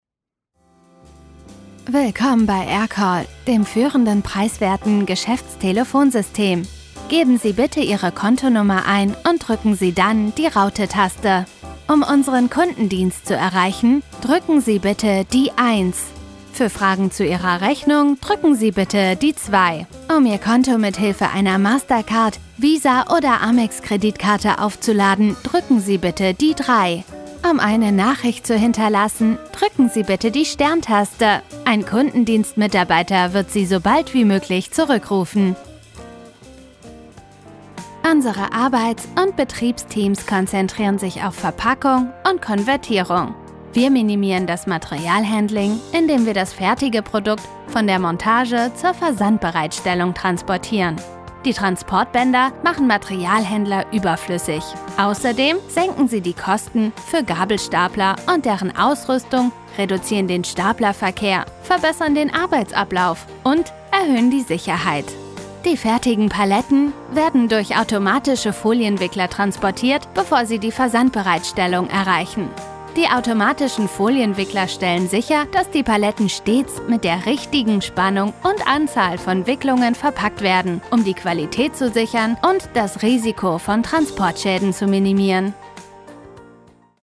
German Voice Overs
Our German voice artists are native speakers that have been selected from the best in class.
Combine this wealth of talent with the pristine audio produced in our in-house professional recording studio and you get a truly "amazing" voice over recording.
Professional recording studio
Mastered to CD quality